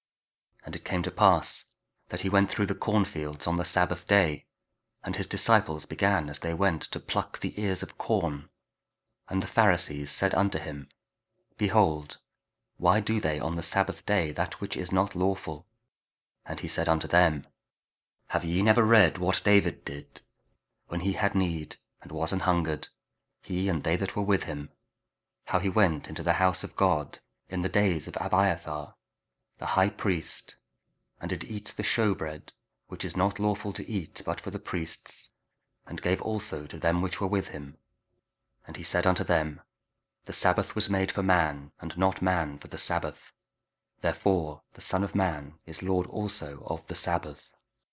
Mark 2: 23-28 – Week 2 Ordinary Time, Tuesday (Audio Bible KJV, Spoken Word)